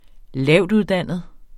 Udtale [ ˈlæwˀduðˌdanˀəð ]